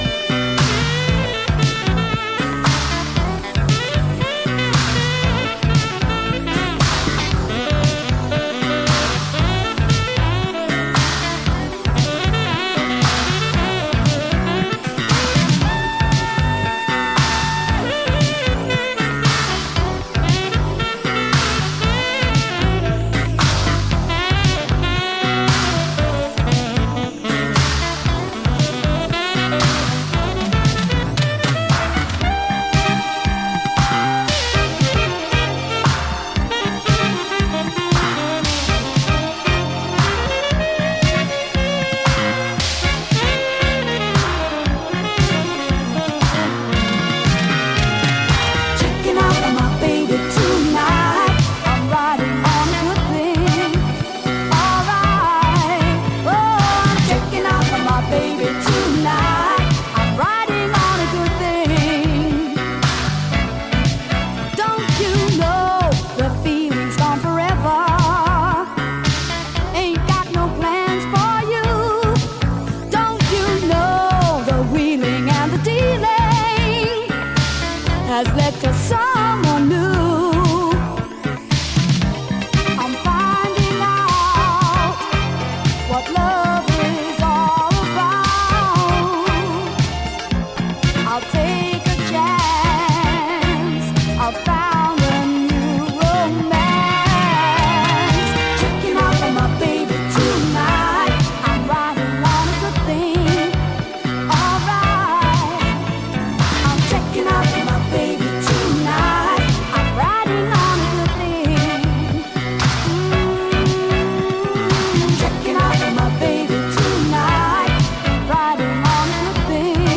UKジャズファンク